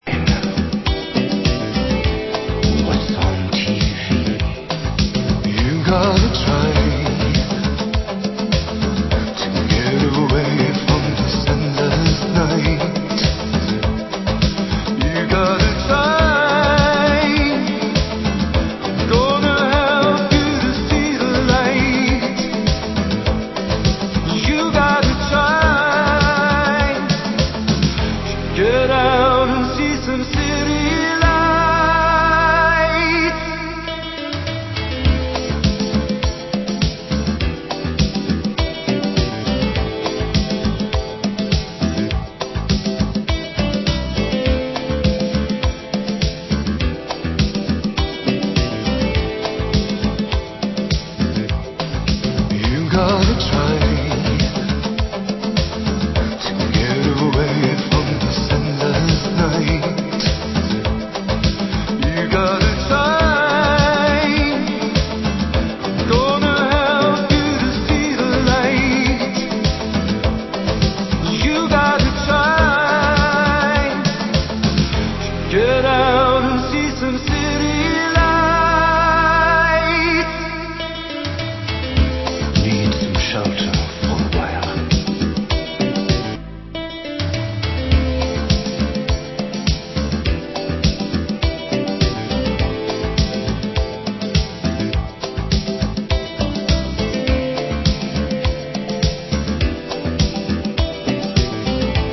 Format: Vinyl 12 Inch
Genre: Synth Pop